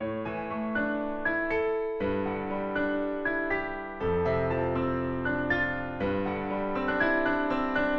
不和谐的和弦
描述：吟游诗人的不和谐的和弦
Tag: 吟游诗人 刺耳的 不和谐的 不和谐的 诗人 音乐 音乐家 集群 曼陀林 中世纪